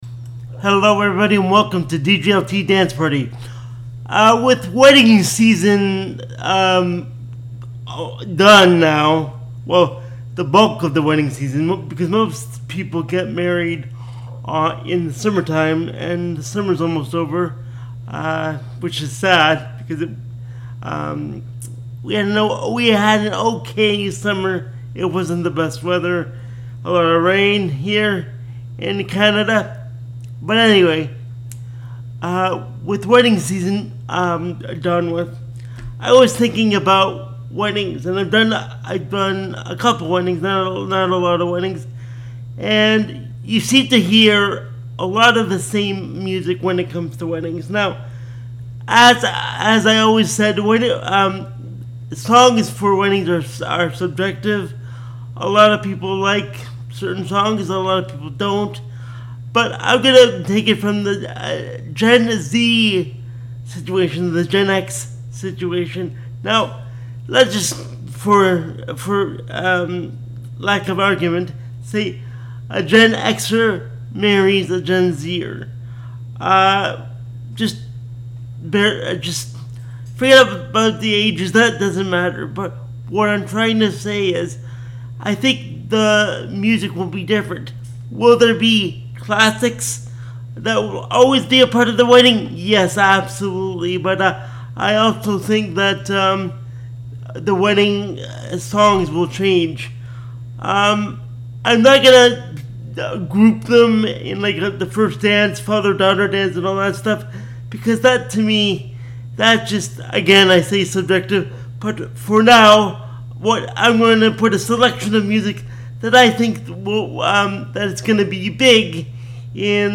If NOT it is the CLEAN Radio Version.